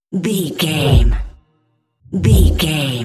Whoosh deep fast
Sound Effects
Fast
dark
futuristic
intense